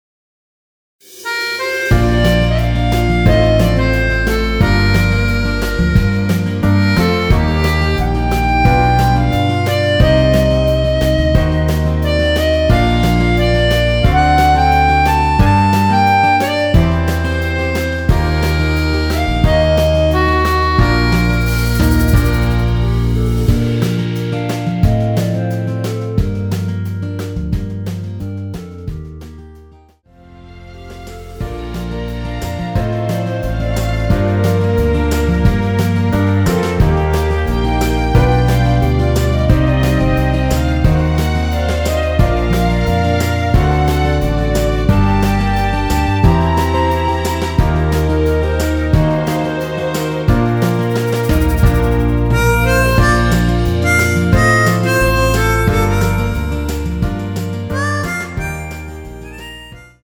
원키 멜로디 포함된 MR입니다.(미리듣기 확인)
Bb
앞부분30초, 뒷부분30초씩 편집해서 올려 드리고 있습니다.
(멜로디 MR)은 가이드 멜로디가 포함된 MR 입니다.